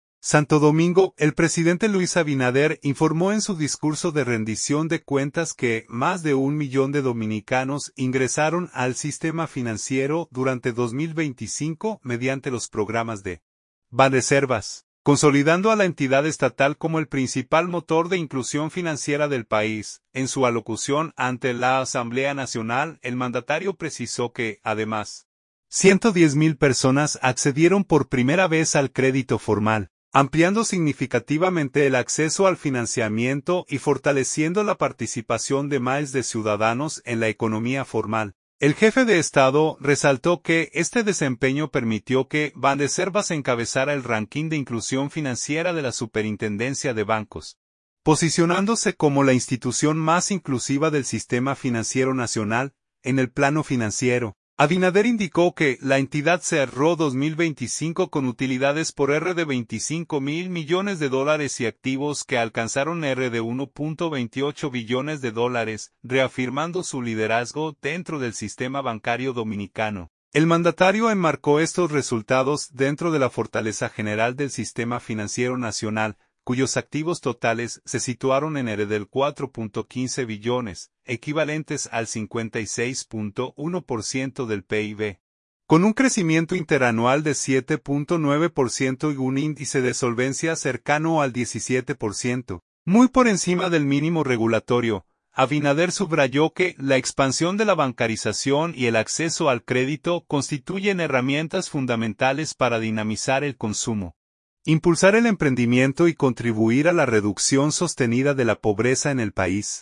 Santo Domingo.– El presidente Luis Abinader informó en su discurso de rendición de cuentas que más de un millón de dominicanos ingresaron al sistema financiero durante 2025 mediante los programas de Banreservas, consolidando a la entidad estatal como el principal motor de inclusión financiera del país.
En su alocución ante la Asamblea Nacional, el mandatario precisó que, además, 110,000 personas accedieron por primera vez al crédito formal, ampliando significativamente el acceso al financiamiento y fortaleciendo la participación de miles de ciudadanos en la economía formal.